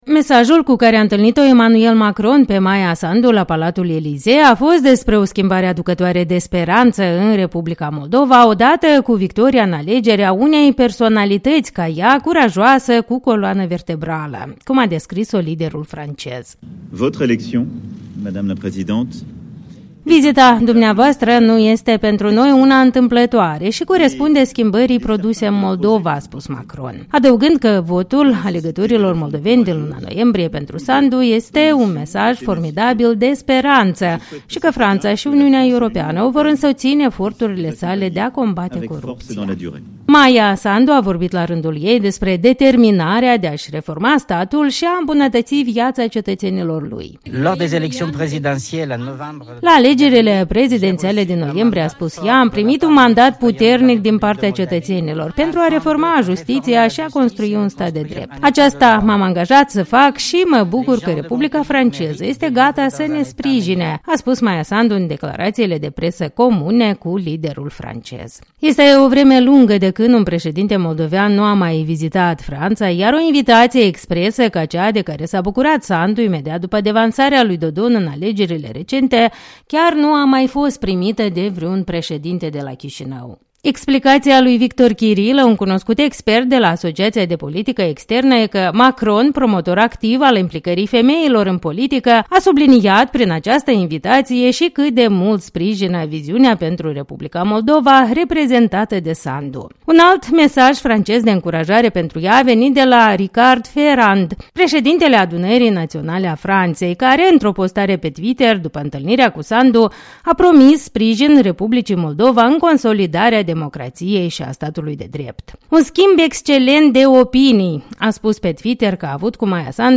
„La alegerile prezidențiale din noiembrie am primit un mandat puternic din partea cetățenilor pentru a reforma justiția și a construi un stat de drept. (...) Aceasta m-am angajat să fac și mă bucur că Republica Franceză este gata să ne sprijine”, a spus Maia Sandu, în declarațiile de presă comune cu liderul francez.